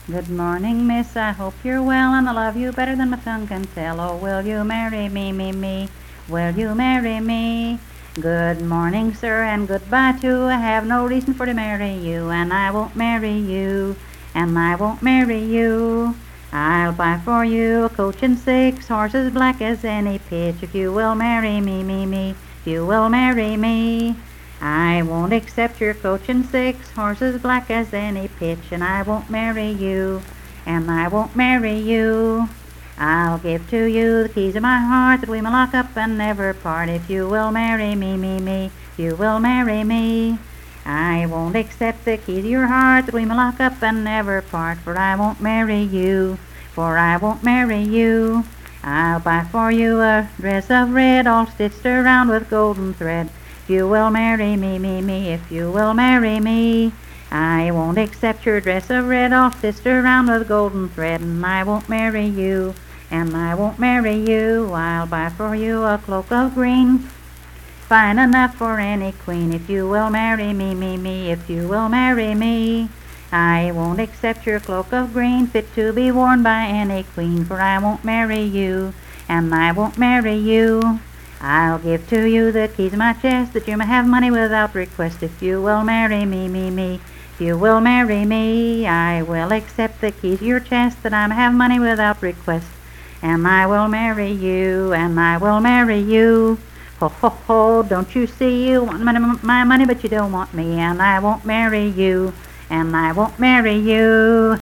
Unaccompanied vocal music
Performed in Coalfax, Marion County, WV.
Children's Songs, Dance, Game, and Party Songs
Voice (sung)